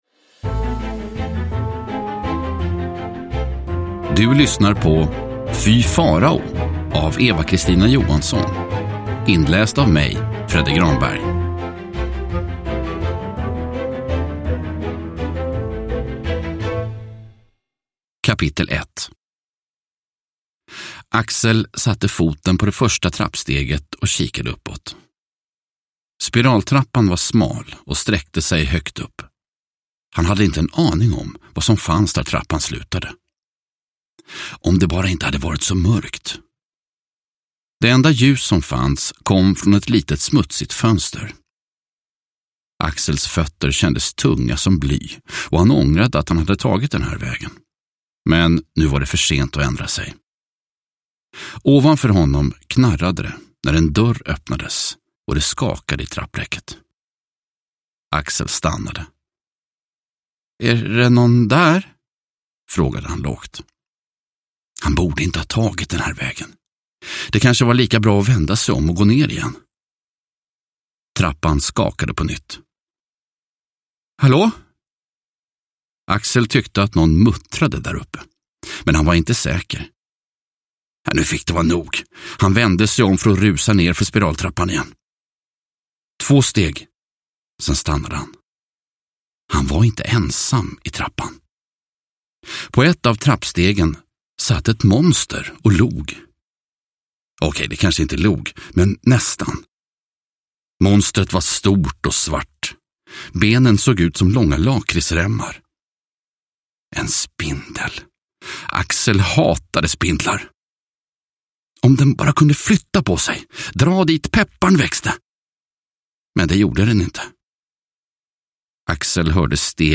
Fy Farao – Ljudbok – Laddas ner